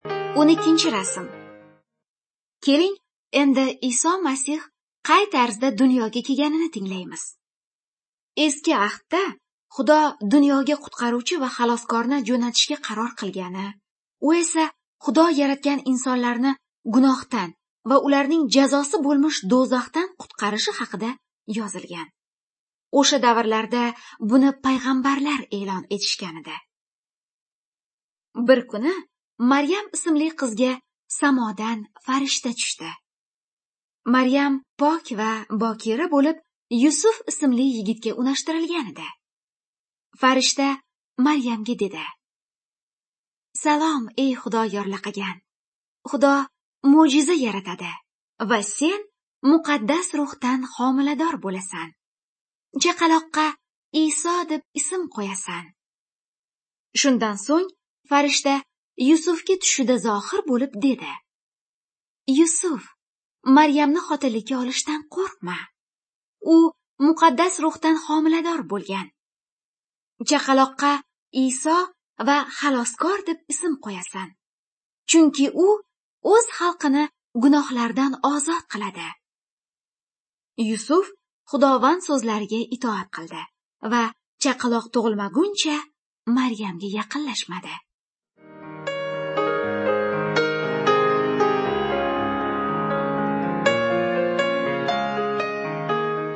Good News (Female)